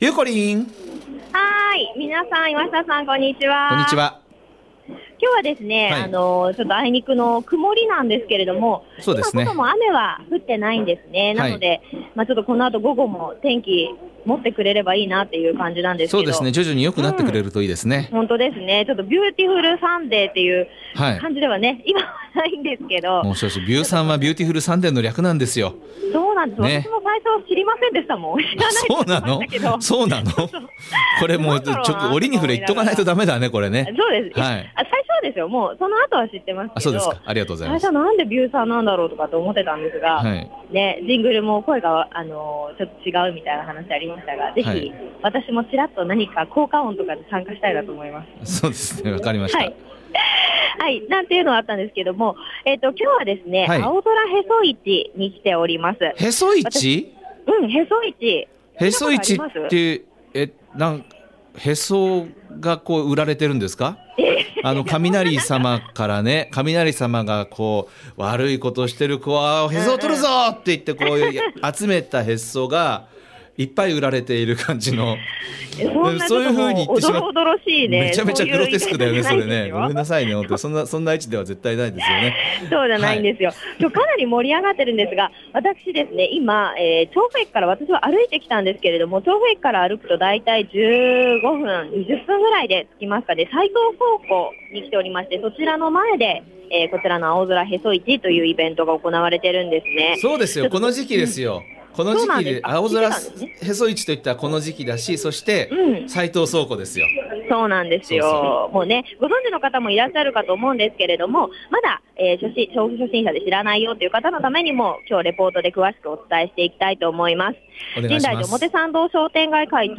第21回、青空へそ市の会場からお伝えいたしましたびゅーサン街角レポート！